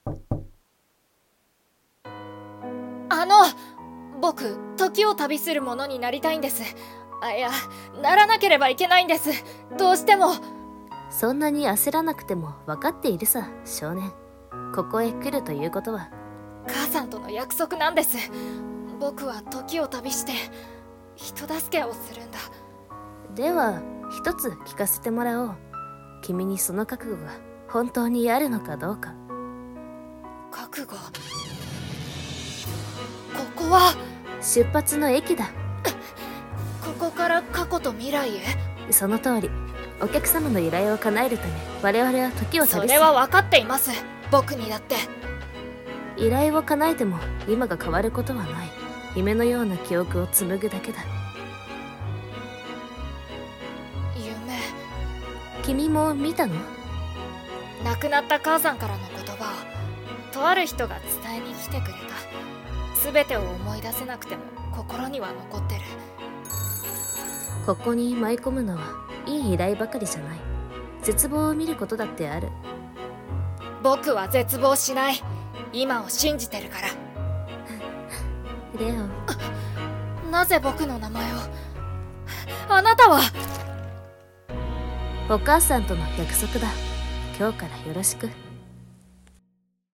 声劇】時を旅する者